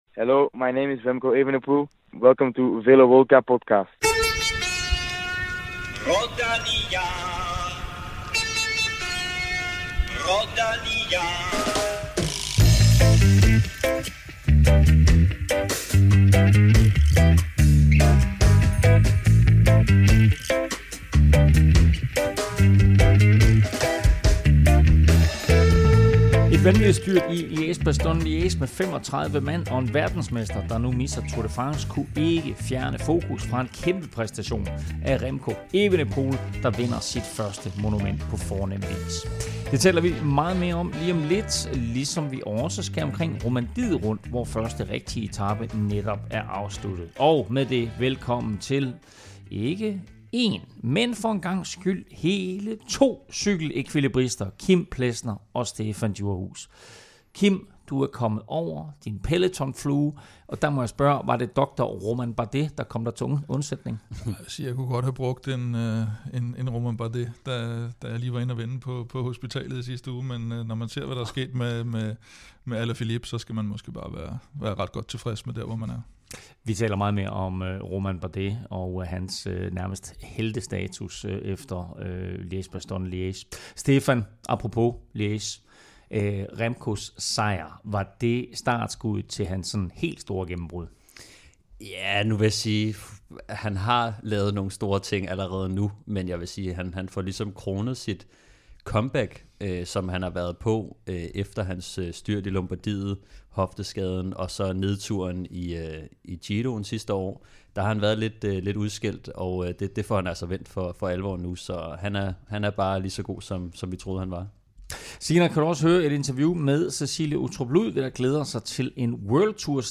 Vi taler også om Giro-opvarmningsløbet Tour of the Alps og om det igangværende Romandiet Rundt, der fik en dramatisk afslutning i dag. Der er interview med Cecilie Uttrup, som glæder sig til det første World Tour løb for kvinder på dansk grund.